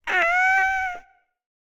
Minecraft Version Minecraft Version snapshot Latest Release | Latest Snapshot snapshot / assets / minecraft / sounds / mob / ghastling / ghastling5.ogg Compare With Compare With Latest Release | Latest Snapshot
ghastling5.ogg